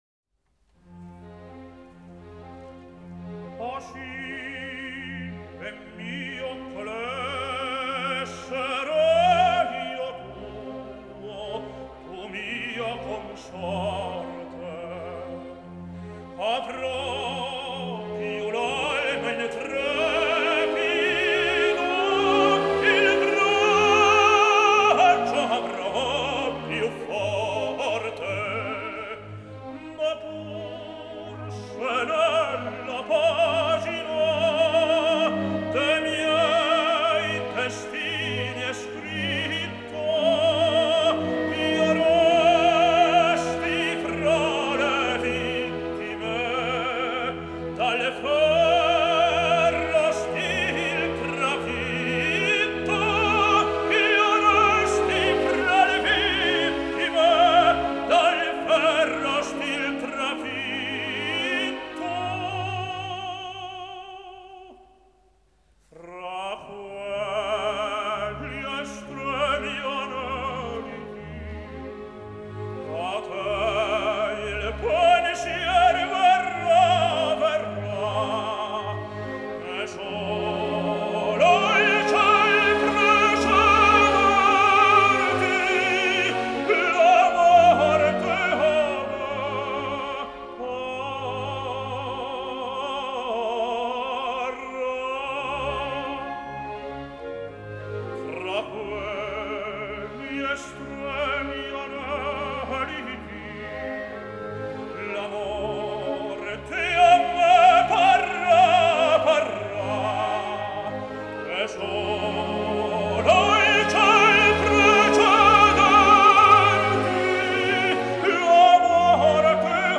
Сегодня исполняется 87 лет великому итальянскому тенору Карло Бергонци!